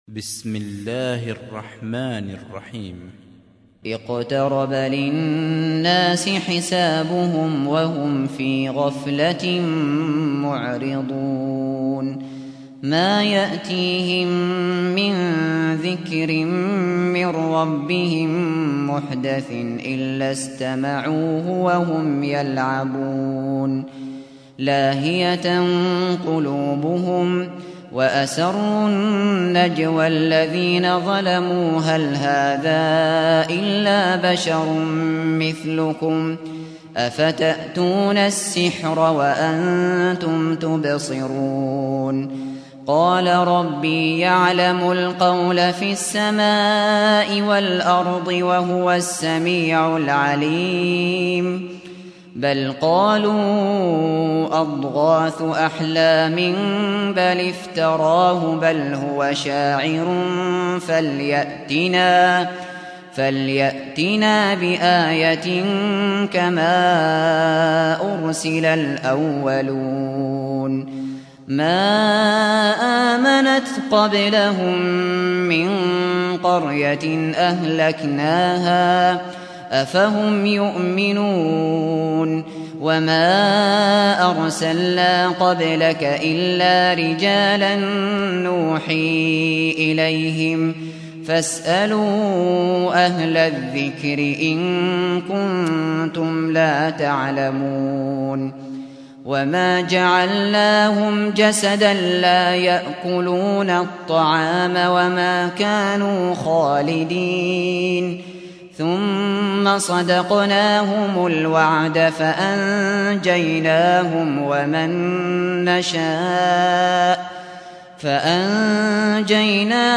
سُورَةُ الأَنبِيَاءِ بصوت الشيخ ابو بكر الشاطري